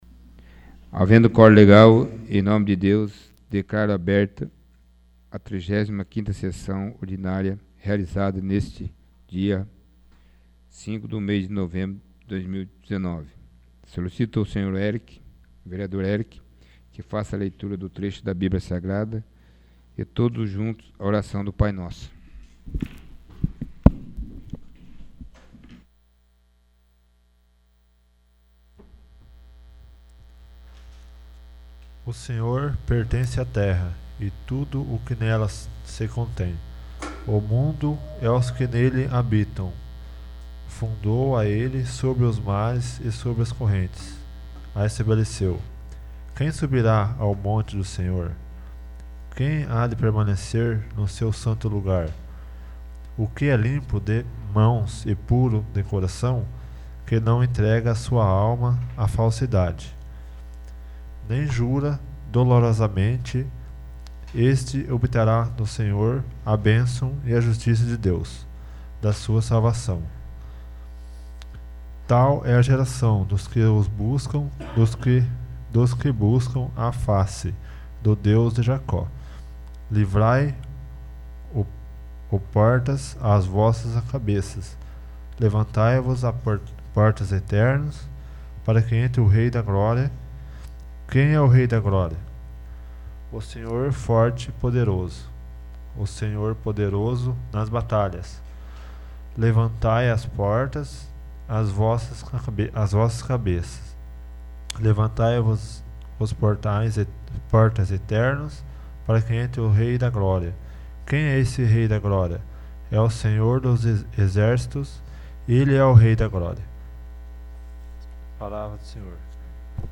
35º. Sessão Ordinária